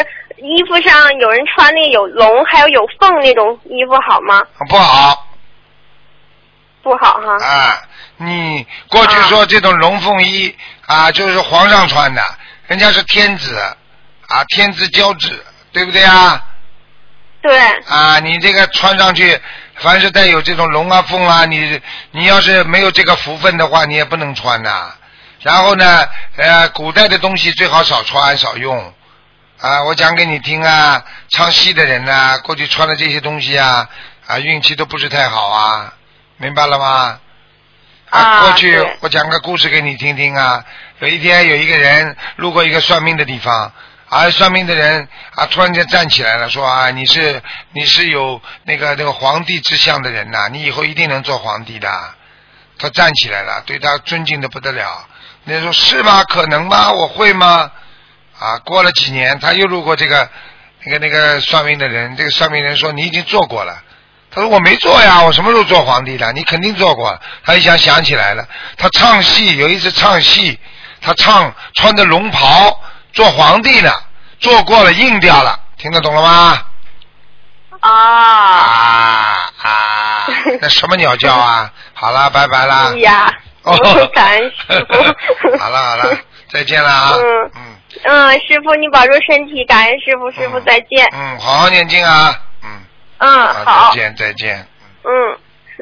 Tanya Jawab
Pendengar wanita: Ada orang yang memakai pakaian bermotif naga dan burung phoenix, apakah bagus?